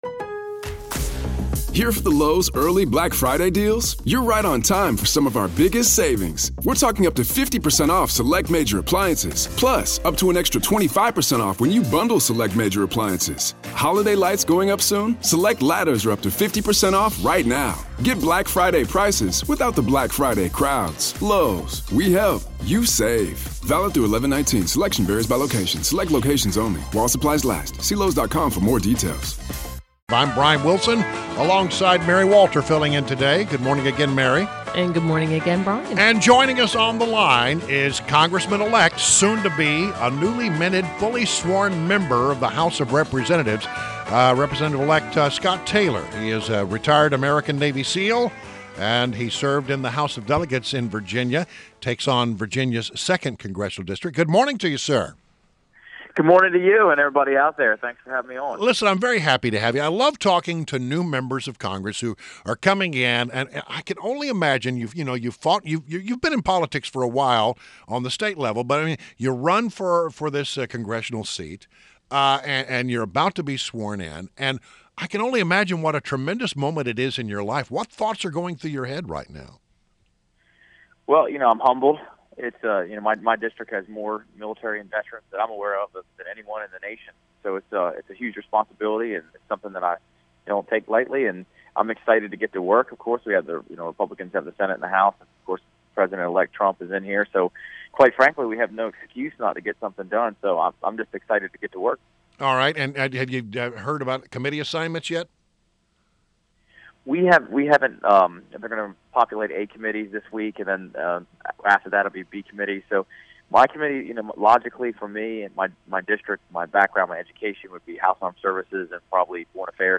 WMAL Interview - SCOTT TAYLOR - 01.03.17
INTERVIEW – Congressman-Elect SCOTT TAYLOR – retired American Navy SEAL and the United States Representative-elect for Virginia’s 2nd congressional district